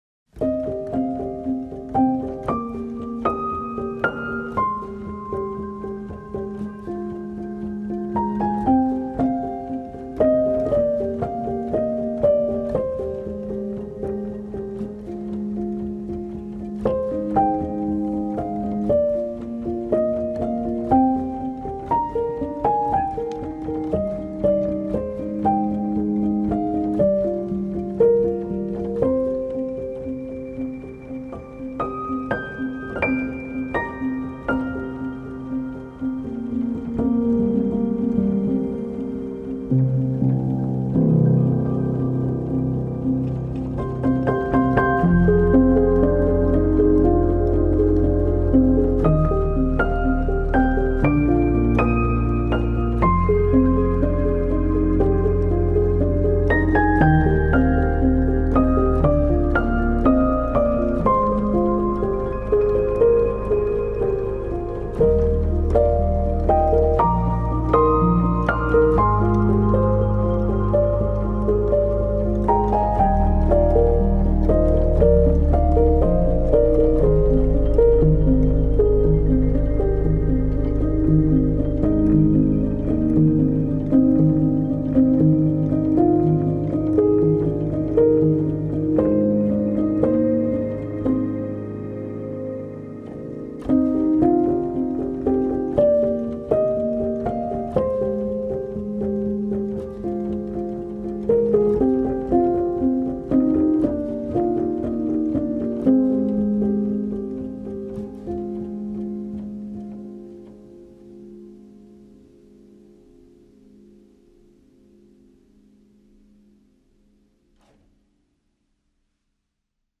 سبک آرامش بخش , پیانو , موسیقی بی کلام
پیانو آرامبخش